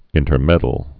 (ĭntər-mĕdl)